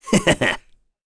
Baudouin-Vox-Laugh1.wav